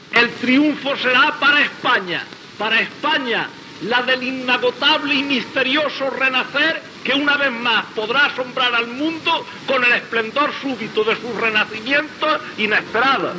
Paraules del president de la II República espanyola Niceto Alcalá Zamora.